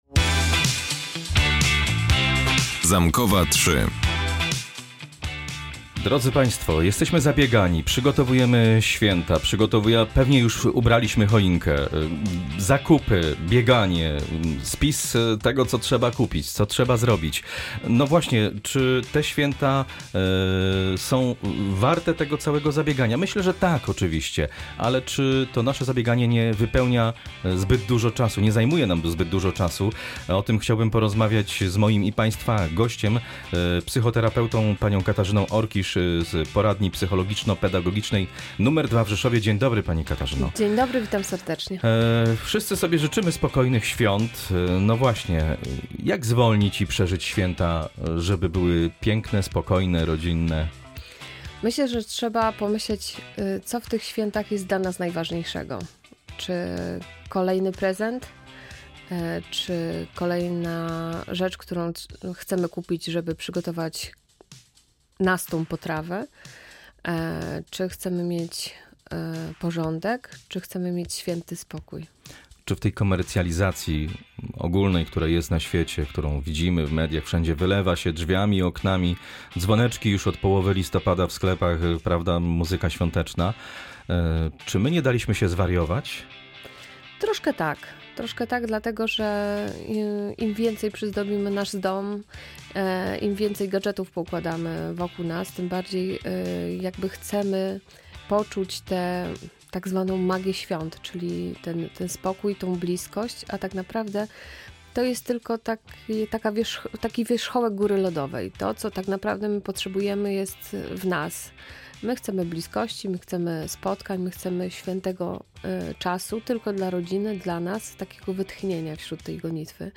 Święty spokój zależy od nas • Audycje • Polskie Radio Rzeszów